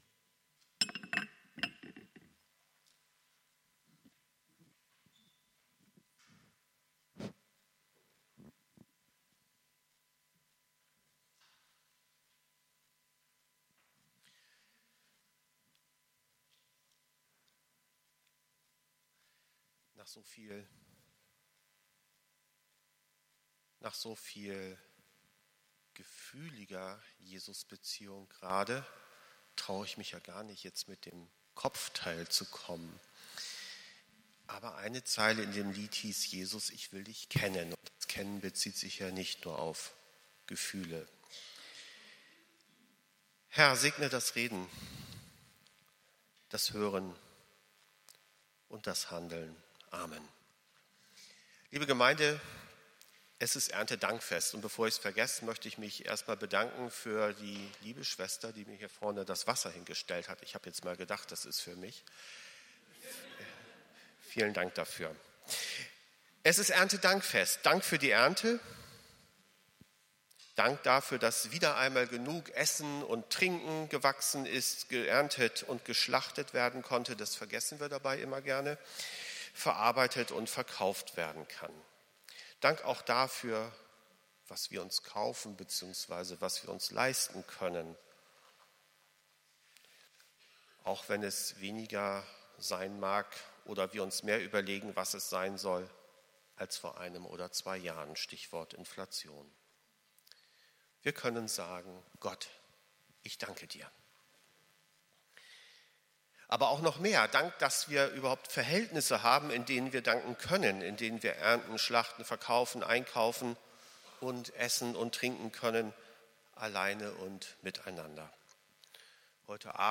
Predigt vom 01.10.2023